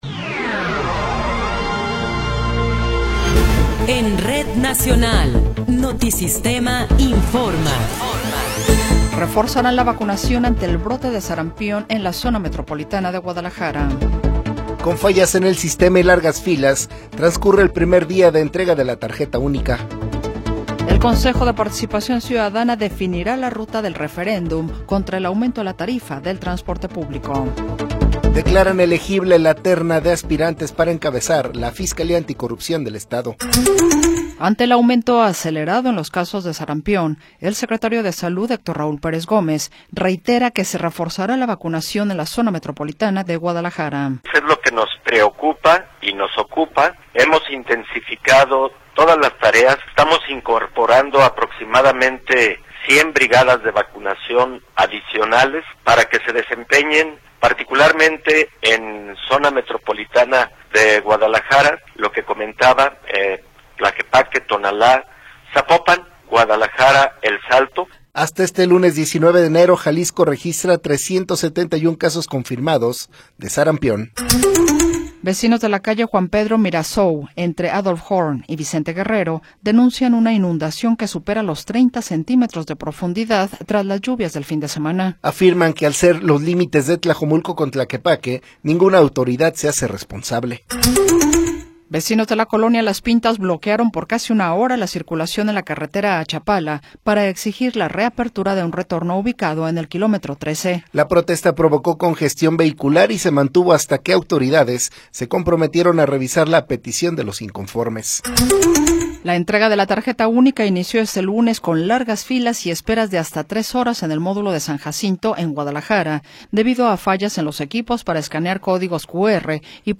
Noticiero 20 hrs. – 19 de Enero de 2026
Resumen informativo Notisistema, la mejor y más completa información cada hora en la hora.